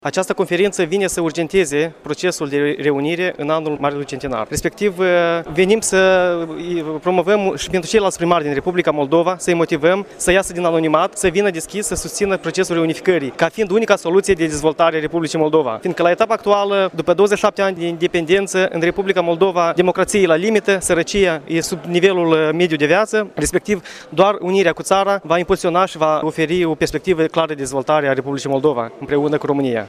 La rândul său, viceprimarul comunei Băcioi, din apropierea Chișinăului, Ilie Leahu, a spus că unirea cu România este singura soluție pentru  dezvoltare a Republicii Moldova, unde domină sărăcia, iar democrația este la limită: